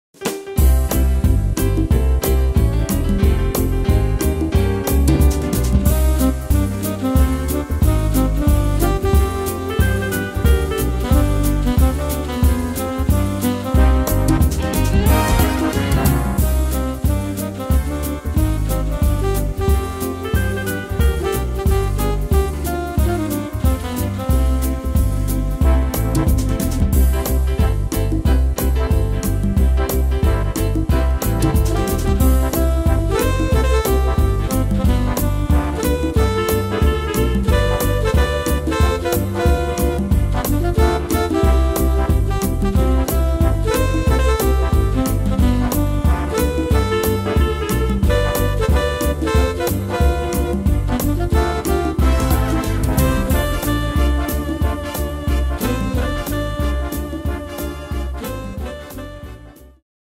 Tempo: 182 / Tonart: C-Dur